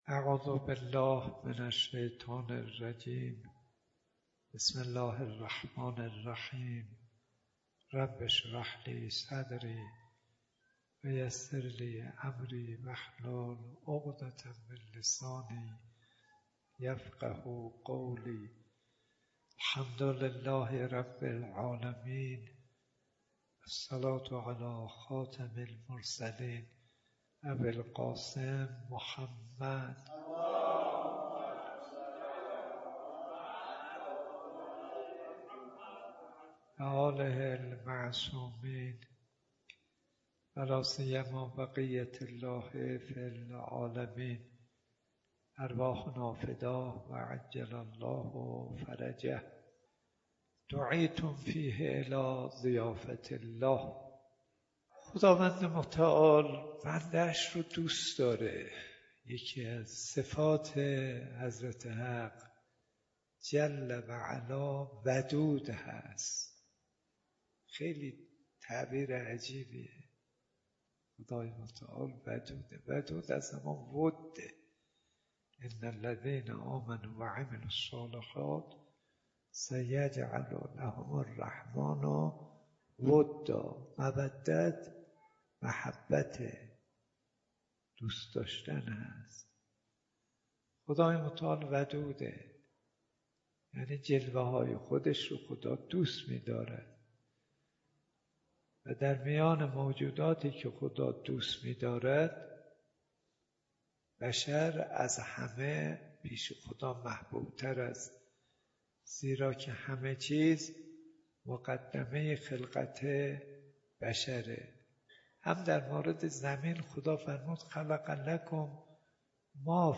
دومین قسمت از بیانات ارزشمند حجت الاسلام کاظم صدیقی با عنوان «ضیافت الهی» ویژه ماه مبارک رمضان